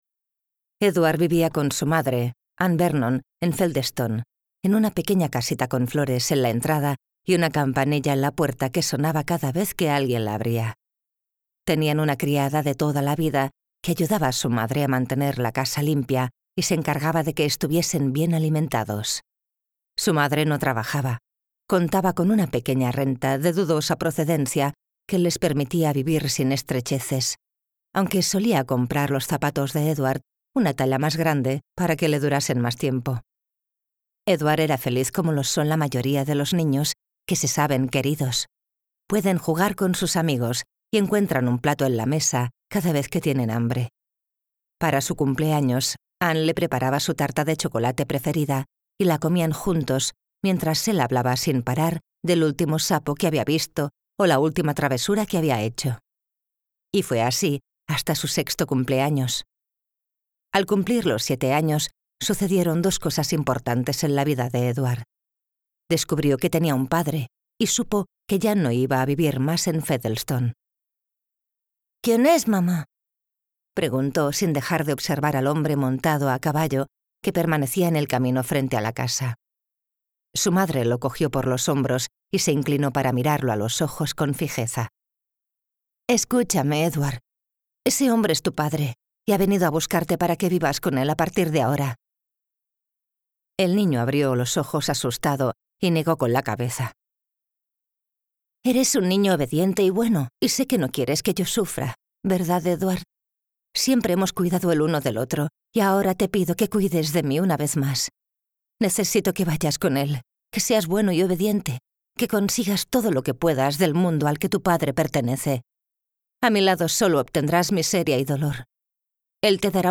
Audiolibro Escrita en tu nombre (Written in Your Name)